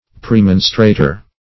Search Result for " premonstrator" : The Collaborative International Dictionary of English v.0.48: Premonstrator \Pre*mon"stra*tor\, n. [L. praemonstrator.]